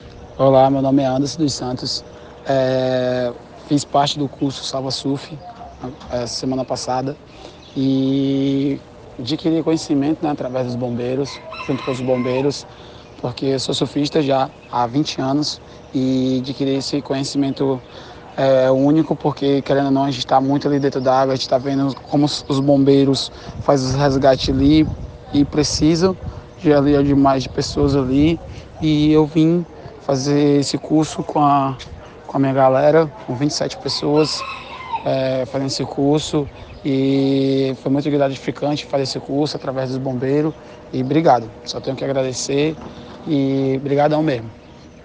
Depoimento
Surfista concludente do Curso Surf Salva.